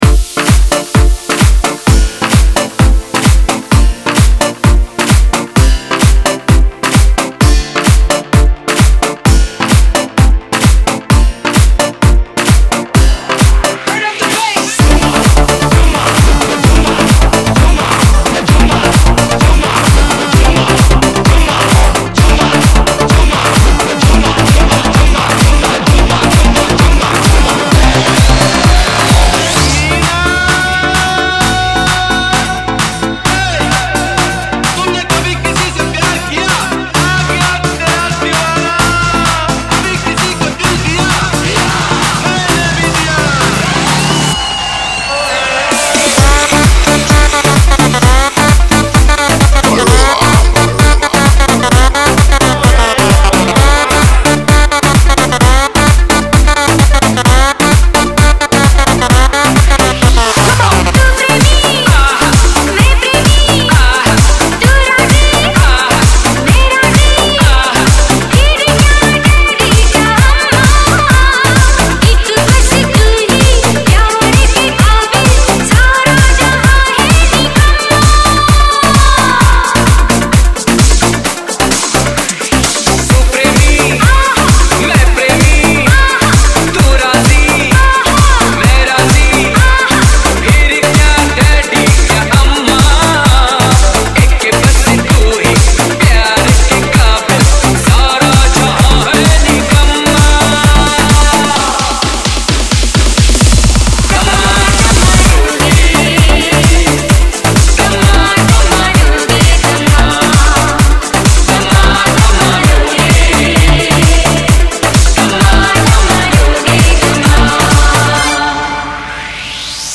Old Hindi DJ Remix Songs